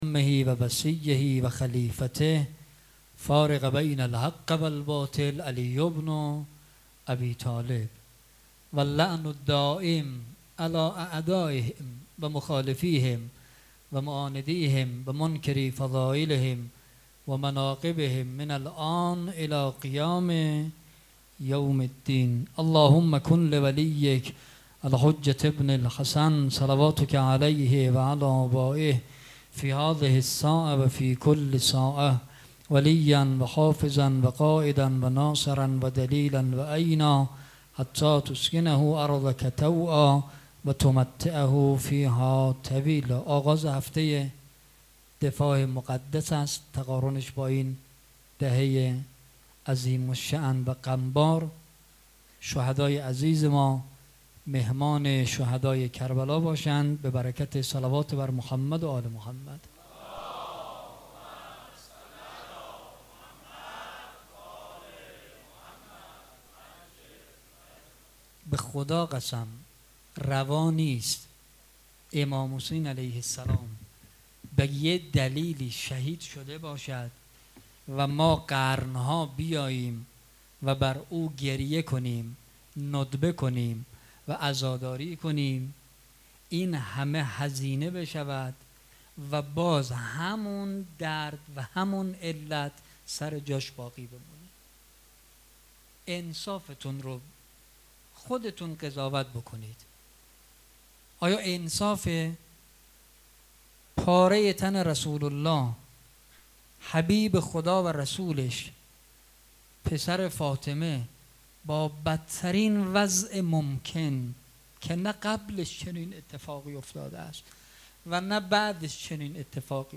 سخنرانی
شب دوم محرم